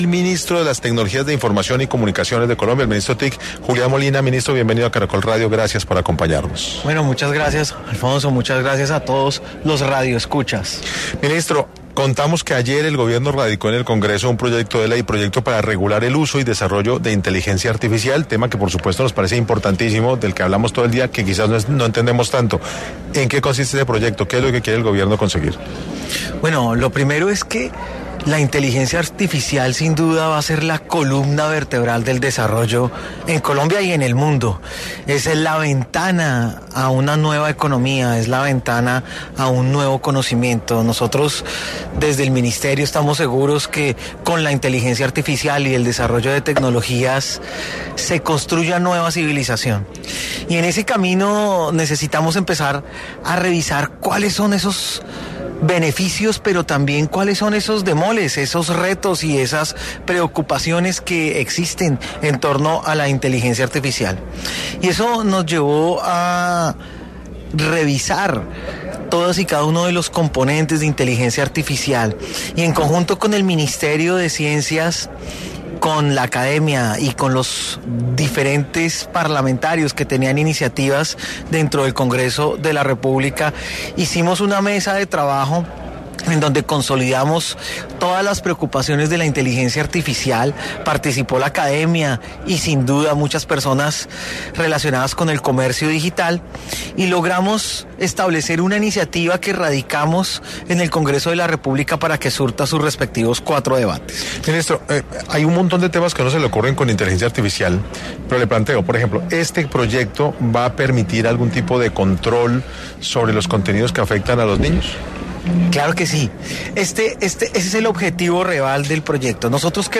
El jefe de cartera, Julián Molina, dio detalles en 6AM del proyecto de ley radicado que busca regular la Inteligencia Artificial en Colombia.